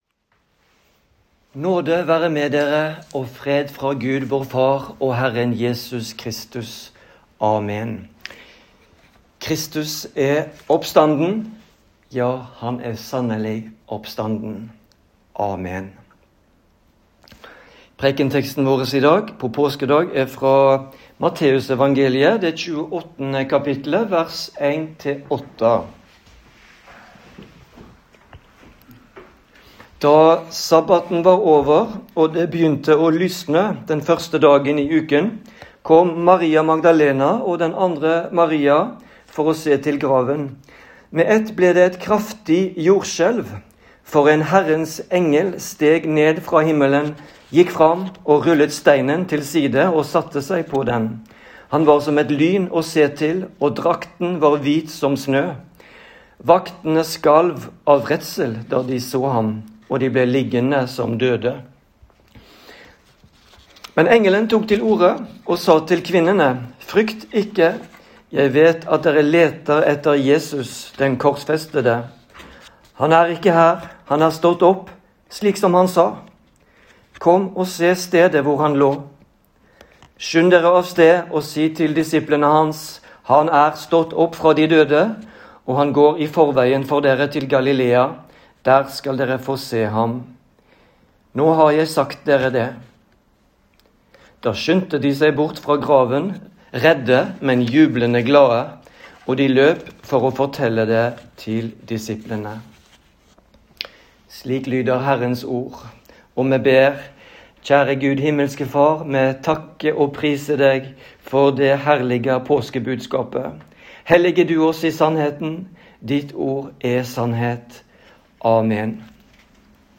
Preken i Den Lutherske Forsamling i Avaldsnes på påskedag 2026